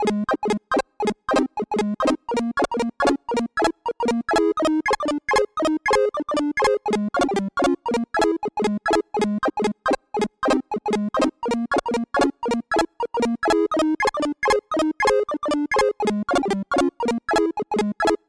LoFi Funk11 C64 SYNTH2 105
这一个是8位的chiptune。
Tag: 105 bpm 8Bit Chiptune Loops Synth Loops 3.08 MB wav Key : A